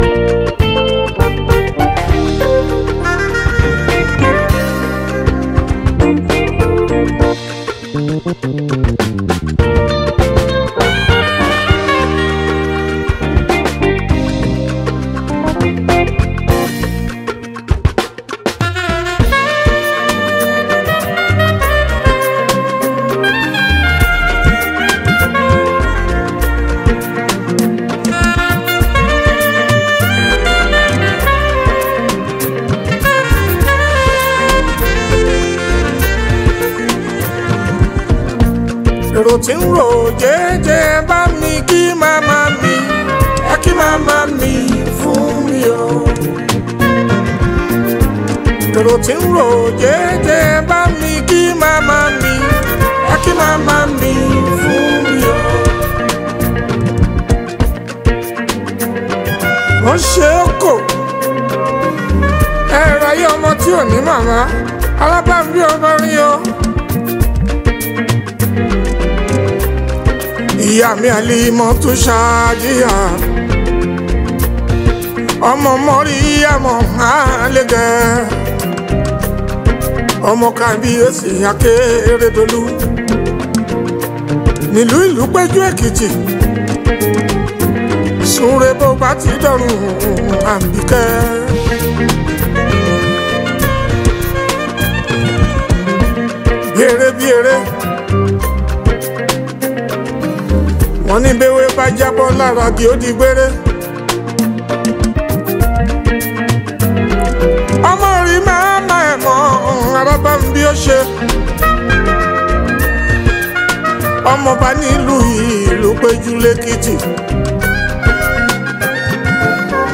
Nigerian Yoruba Fuji track